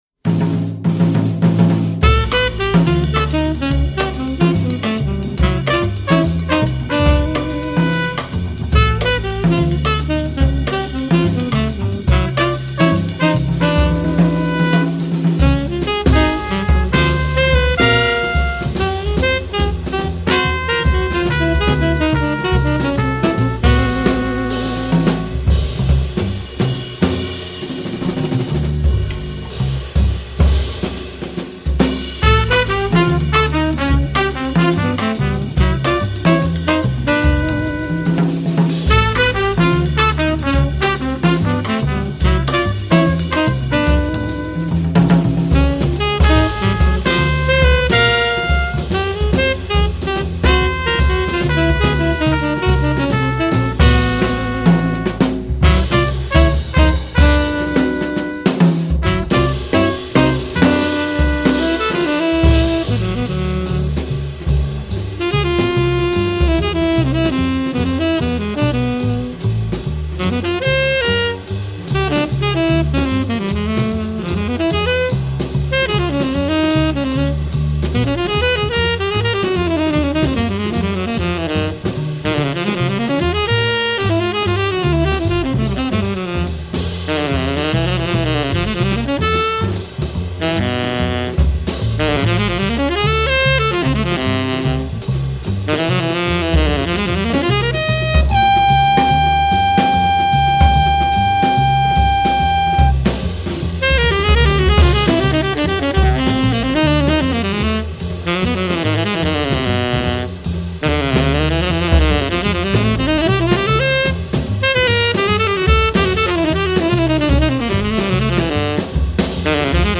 kontrabaxua
bateria
tronpeta